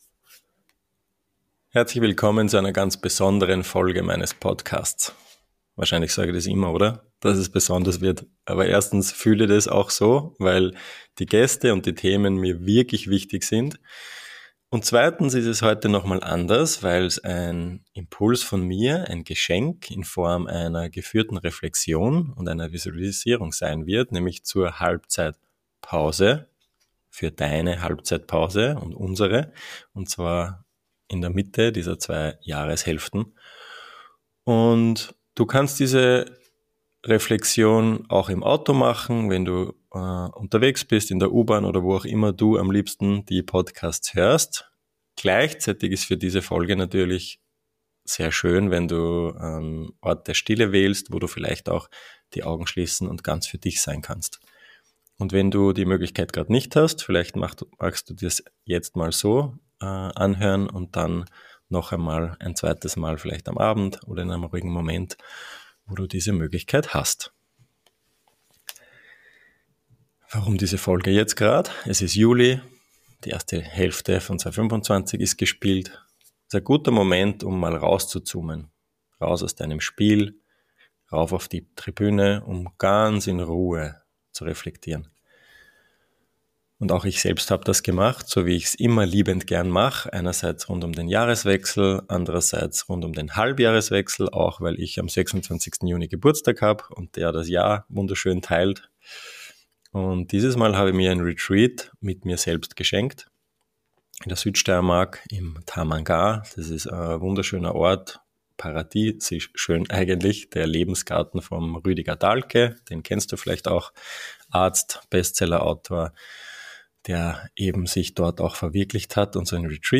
Eine Visualisierung zur Reflexion. Pünktlich zur Halbzeit des Jahres lade ich dich in die Halbzeitpause ein. Eine Einladung innezuhalten, loszulassen und dich neu auszurichten.